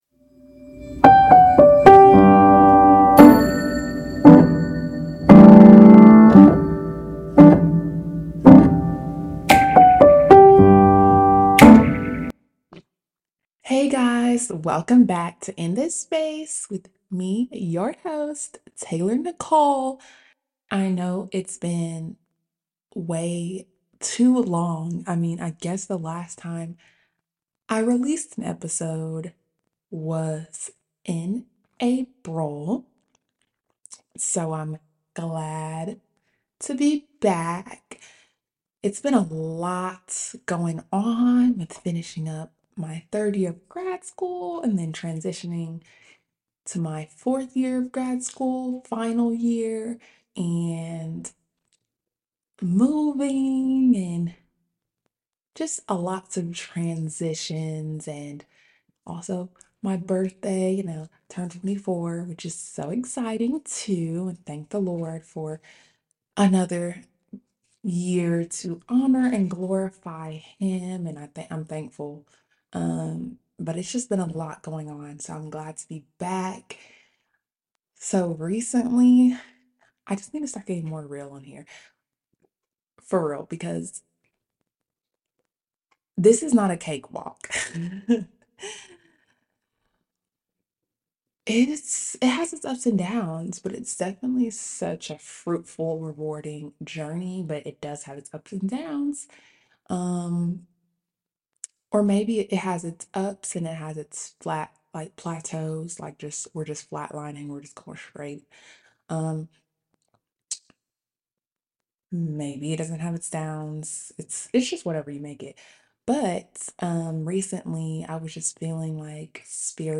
P.S. I messed up my audio again! I forget to turn on a certain setting!